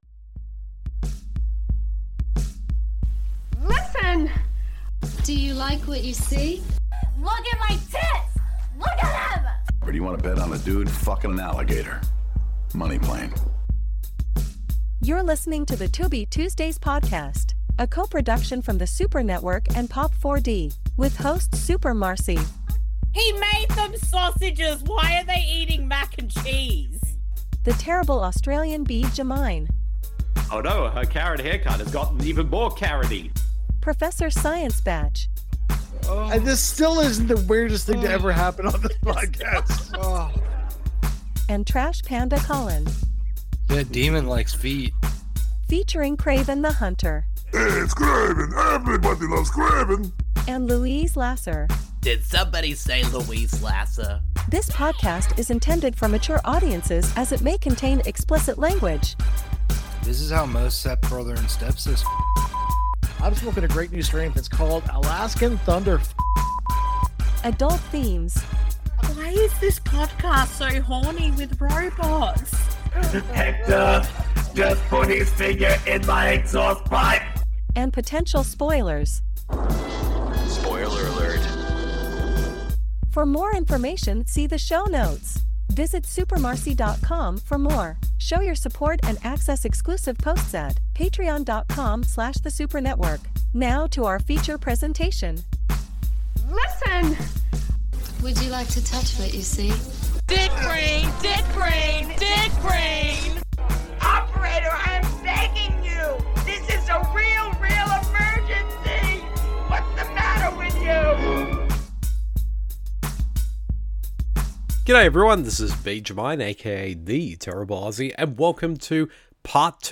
DISCLAIMER: This audio commentary isn’t meant to be taken seriously, it is just a humourous look at a film.